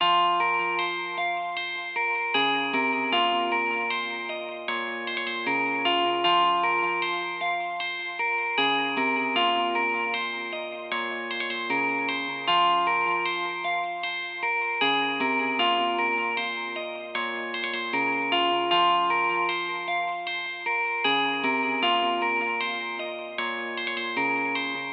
驾车的黑暗吉他合成音符1
描述：F小调合成器延时电吉他
Tag: 154 bpm Trap Loops Guitar Electric Loops 4.19 MB wav Key : F